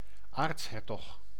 Ääntäminen
Ääntäminen France: IPA: /aʁ.ʃi.dyk/ Haettu sana löytyi näillä lähdekielillä: ranska Käännös Ääninäyte Substantiivit 1. aartshertog Suku: m .